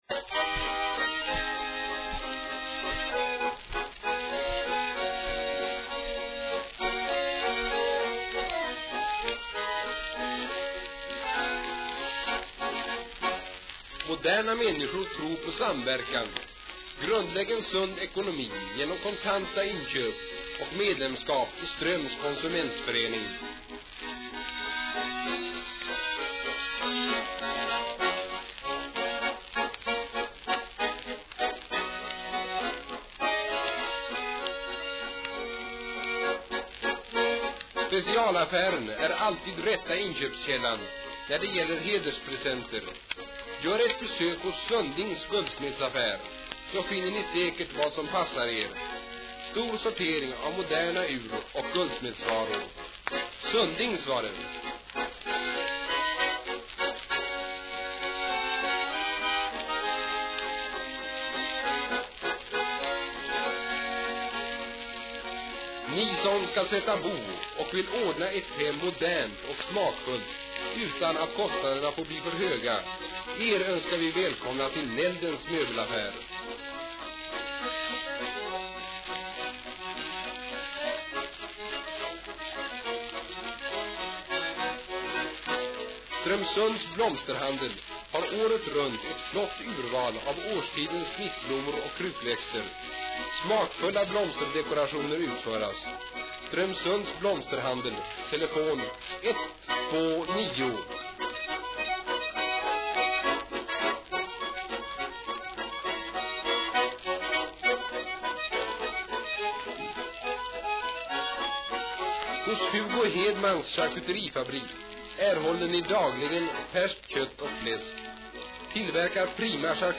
reklamskiva för Strömsundsföretag.